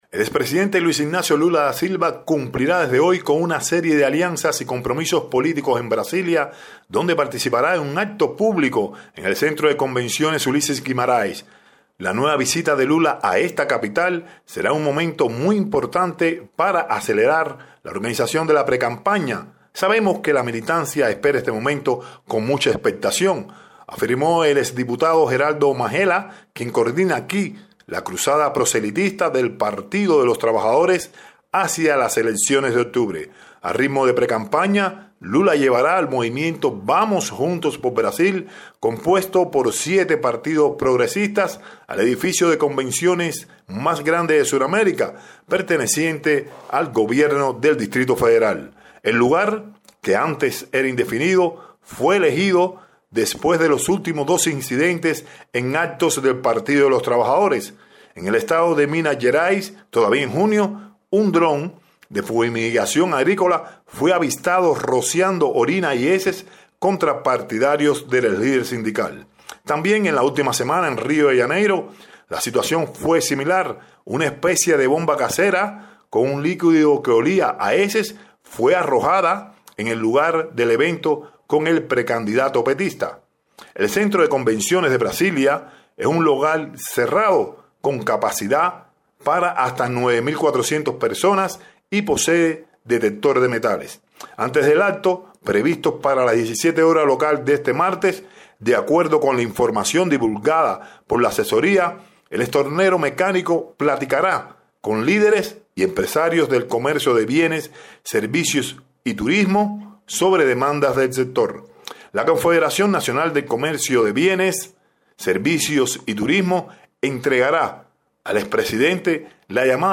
desde Brasilia